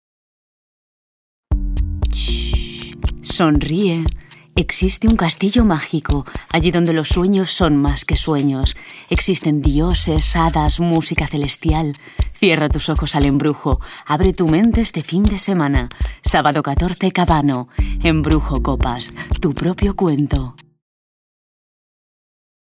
Voz cálida y expresiva.
kastilisch
Sprechprobe: Sonstiges (Muttersprache):